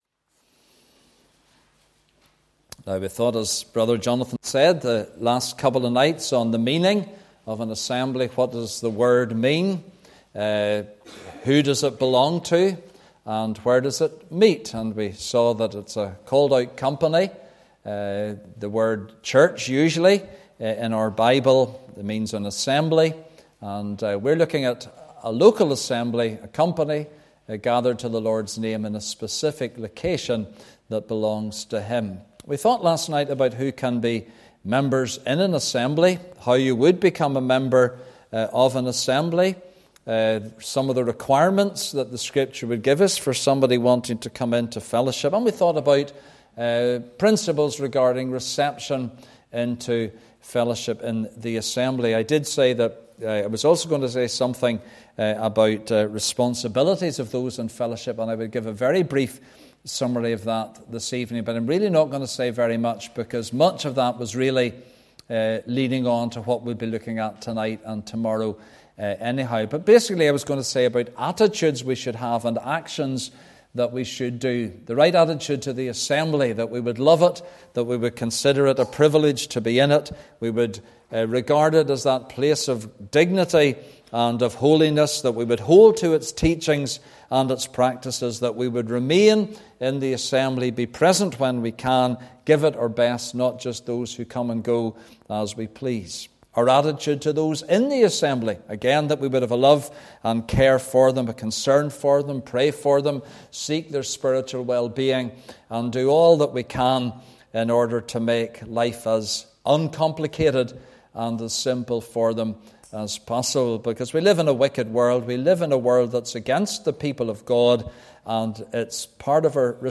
(Message preached Saturday 27th January 2024)